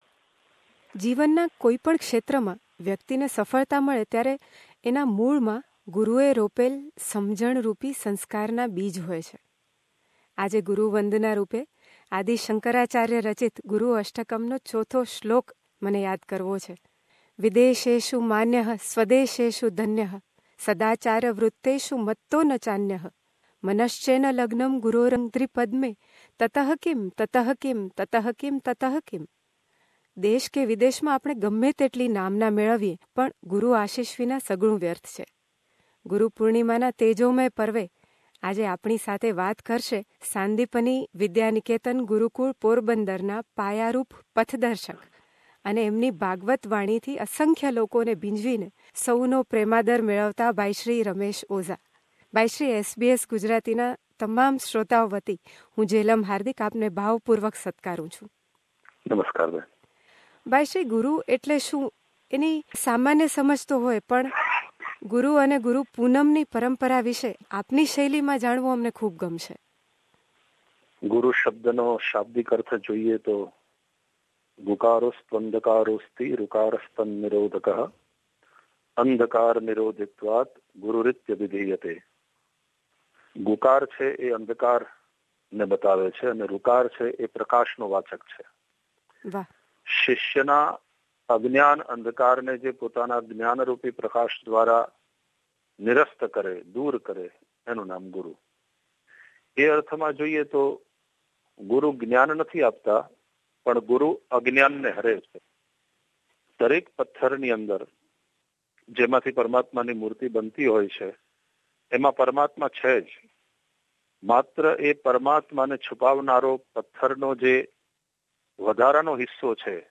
મુલાકાતમાં